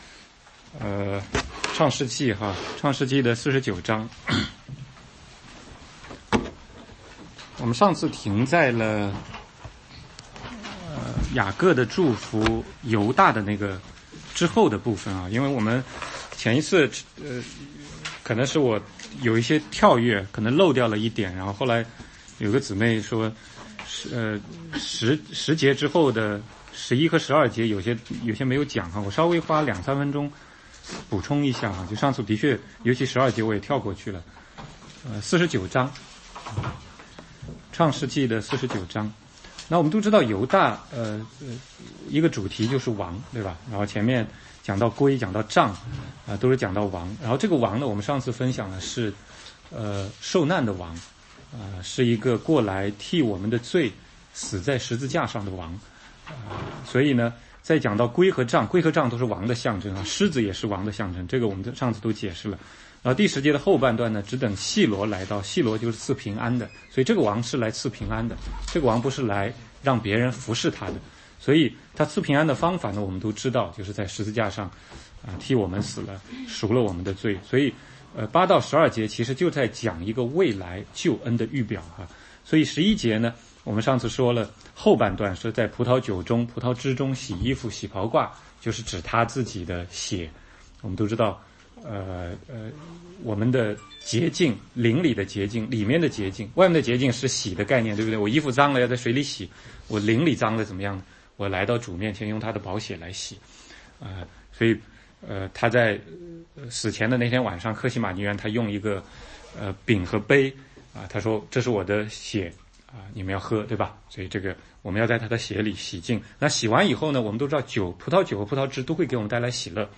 16街讲道录音 - 创世纪